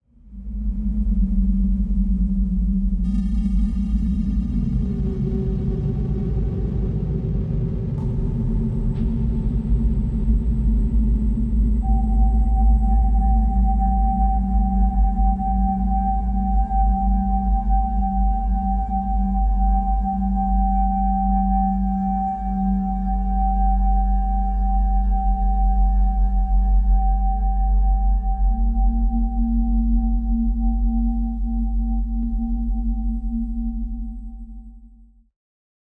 Complex CInematic Mystery Sounds